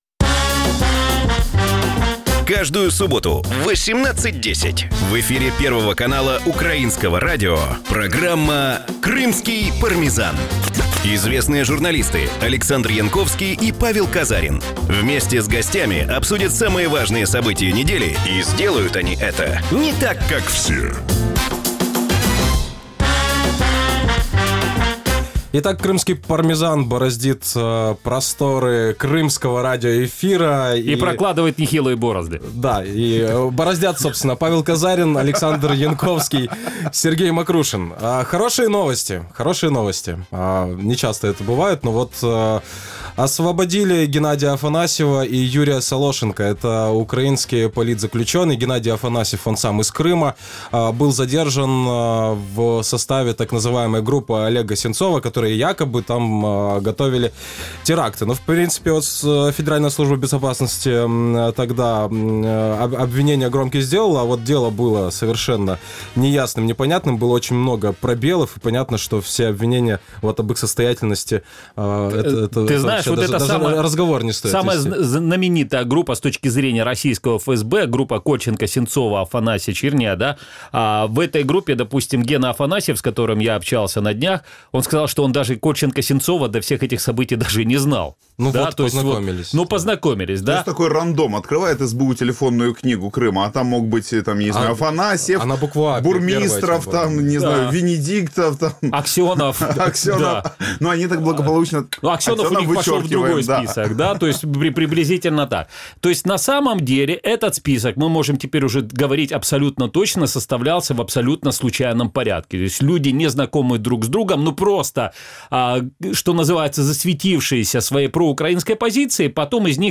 Радио Крым.Реалии каждую субботу в эфире первого канала Украинского радио представляет блогерскую программу «Крымский.Пармезан».